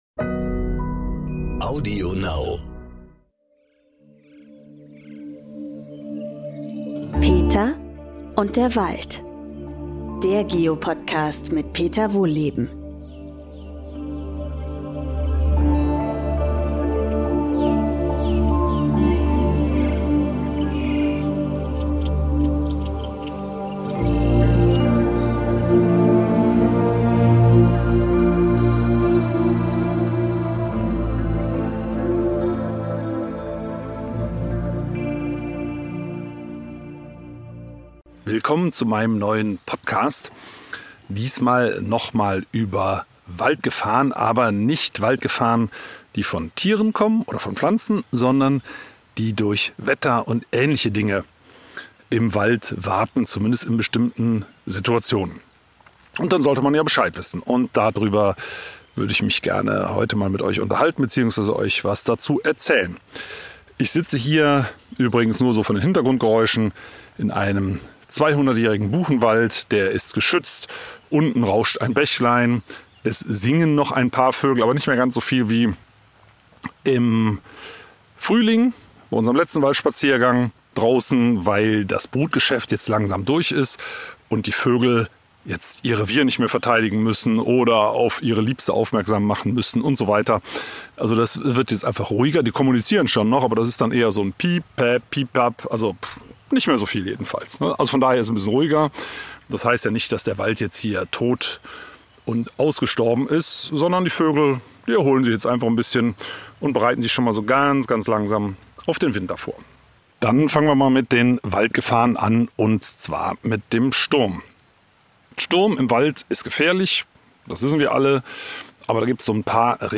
Doch es gibt Tage mit Wetterlagen, an denen man den Wald lieber meiden sollte. Peter Wohlleben nimmt uns mit auf einen Spaziergang durch den Wald und erklärt uns, warum Gewitter und Stürme, aber auch Nebel und Nassschnee uns in gefährliche Situationen bringen kann.